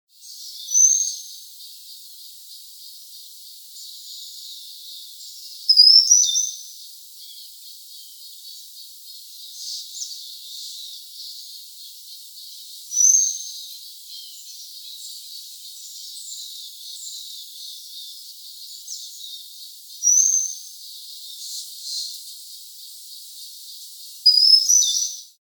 Cedar Waxwing (Bombycilla cedrorum)
a. A high lisp or zeee; sometimes slightly trilled (P).
A hissing, high-pitched, lisping ssse sssee seee (F).
A soft, high-pitched, trilled whistle (N).
Example (from perch on tree top).
cedarwaxwing01.mp3